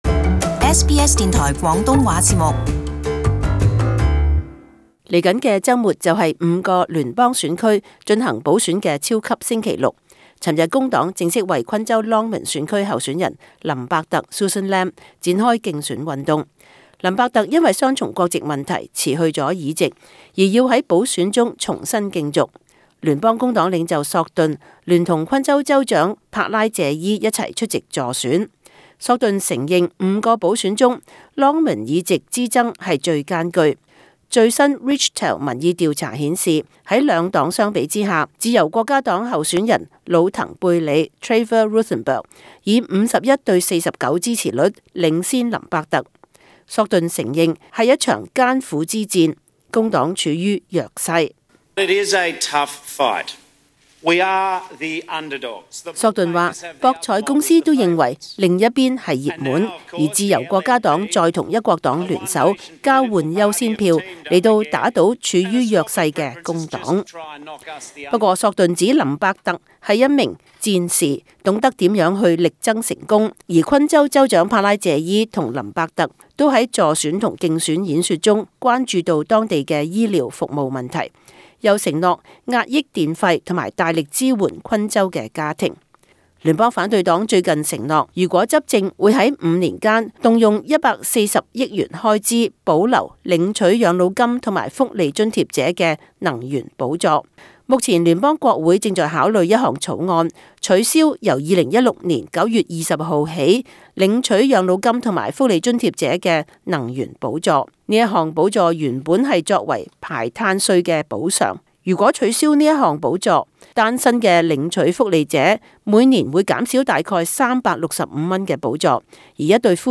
【時事報導】工黨為超級星期六補選加強口號